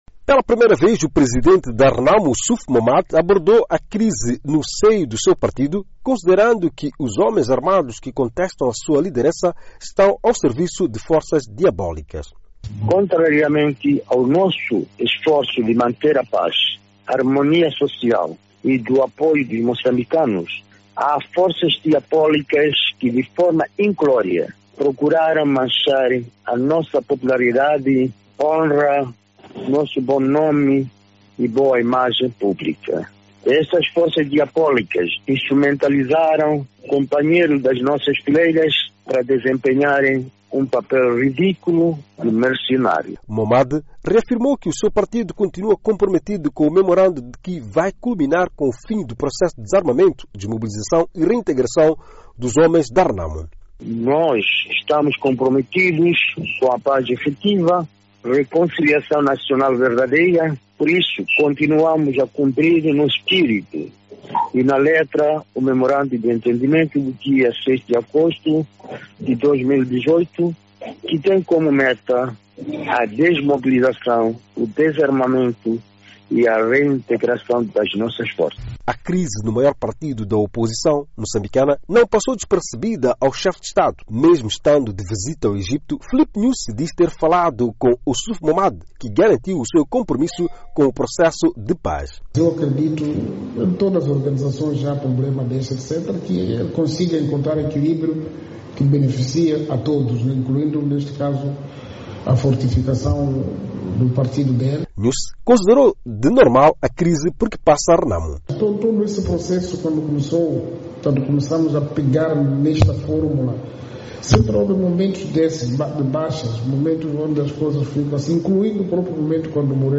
Falando nesta segunda-feira, 25, em teleconferência, a partir da Serra da Gorongosa, Momade disse que os homens armados que contestam a sua liderança estão ao serviço de “forças diabólicas”.